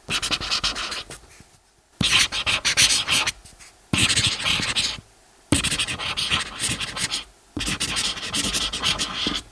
描述：索尼PCM D100Logic专业西班牙XGirona
Tag: 绘图 标记 签名 lapiz 铅笔